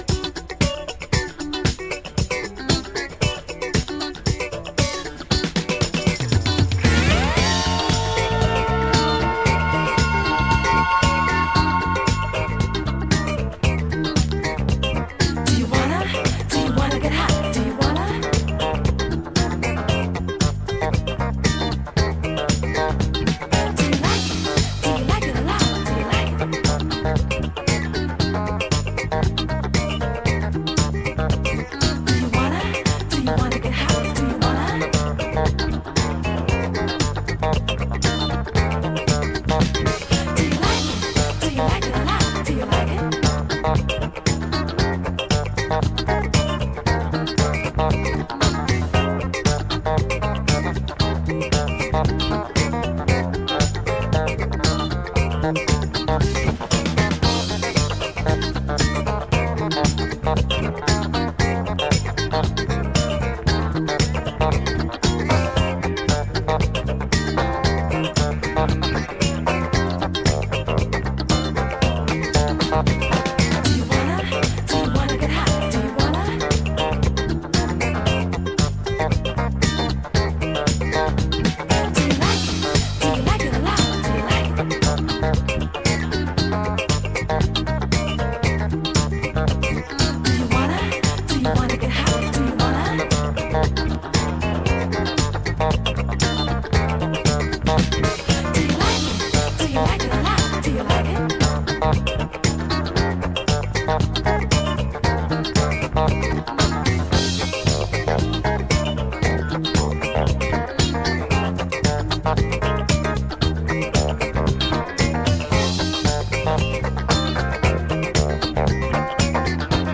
Hammond B3 organ
superb disco-funk nugget
Funk / Soul